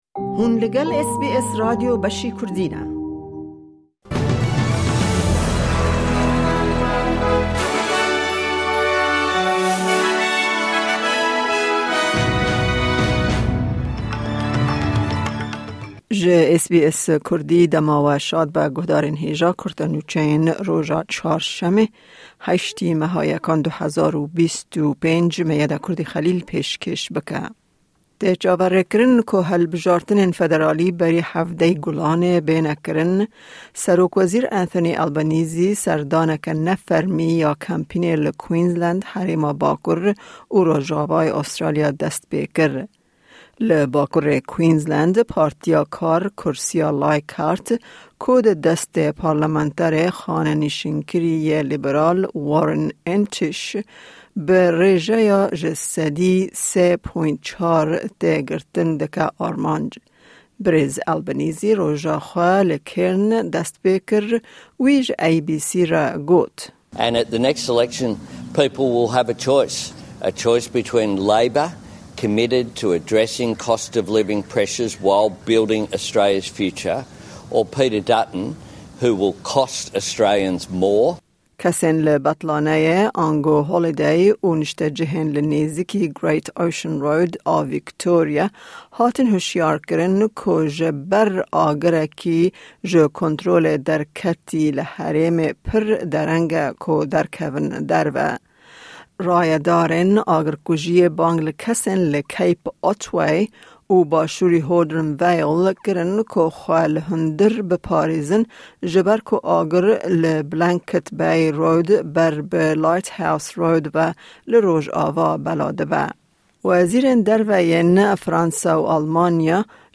Kurte Nûçeyên roja Çarşemê, 8î Çileya 2025